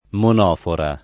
DOP: Dizionario di Ortografia e Pronunzia della lingua italiana
monofora